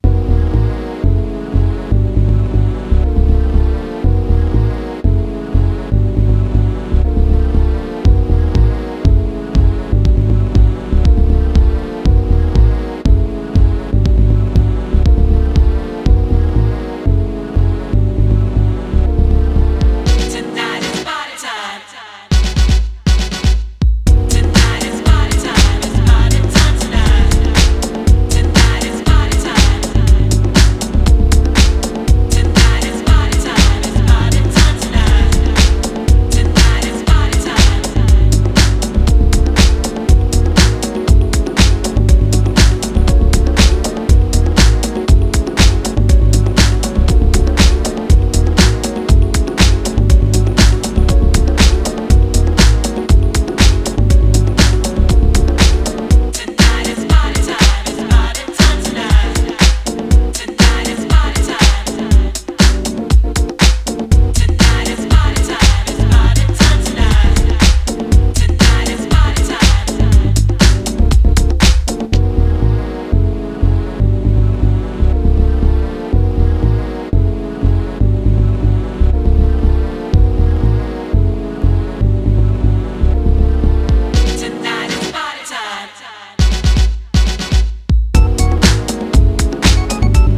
ディープ・ハウスの真髄を突いている、といっても過言では無いでしょう。